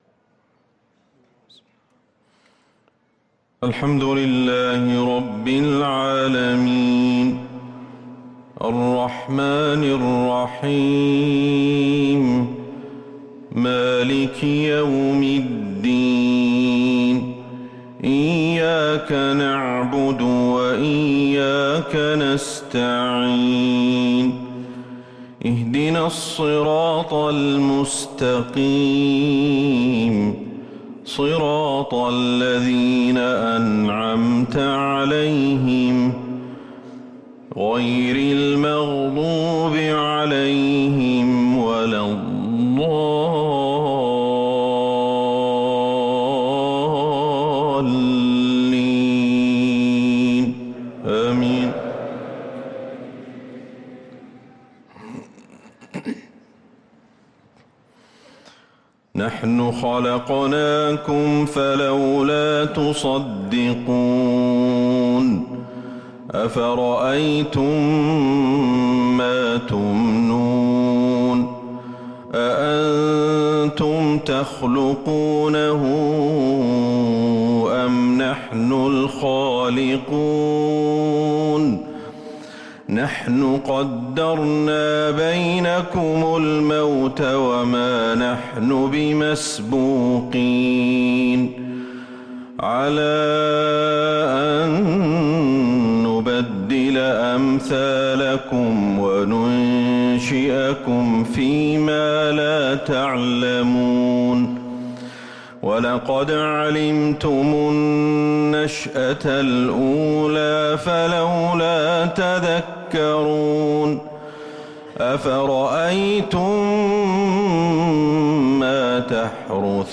صلاة العشاء للقارئ أحمد الحذيفي 28 ربيع الأول 1444 هـ
تِلَاوَات الْحَرَمَيْن .